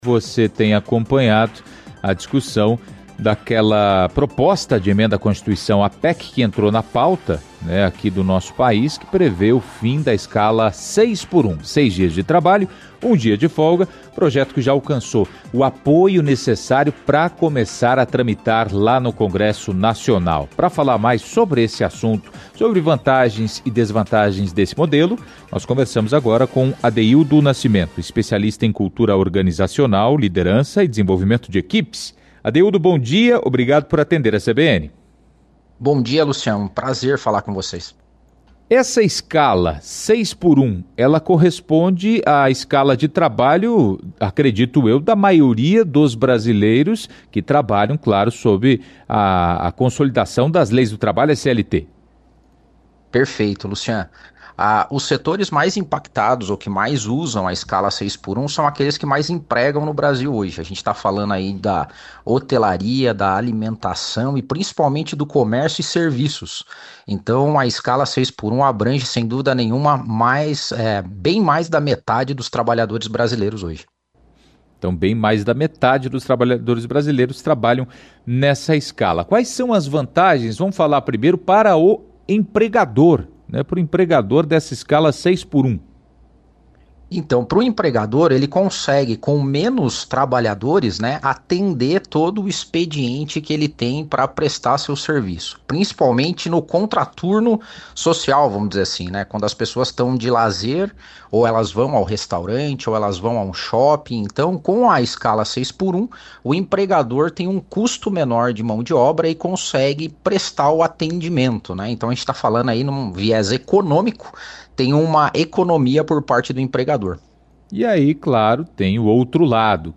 Para falar sobre as vantagens e desvantagens desse modelo, nós conversamos, no CBN Curitiba 1ª Edição de hoje (5)